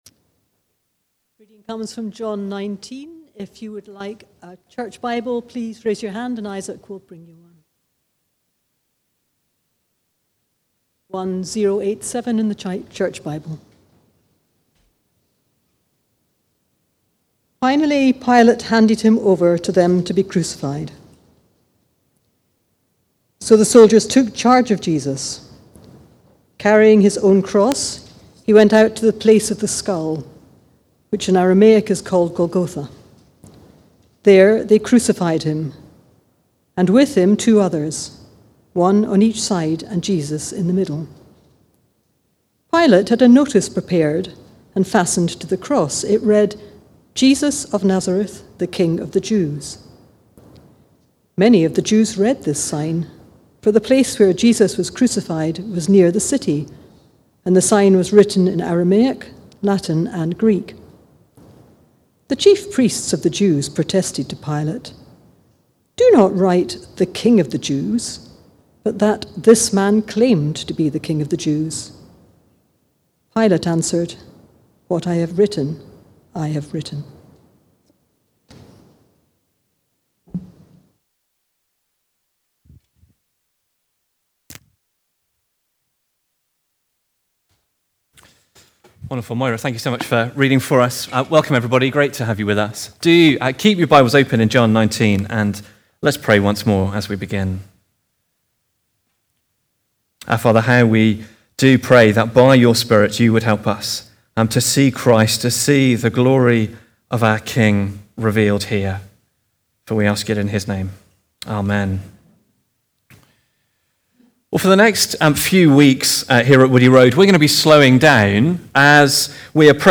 The Sign (John 19:16-22) from the series Life From Death. Recorded at Woodstock Road Baptist Church on 08 March 2026.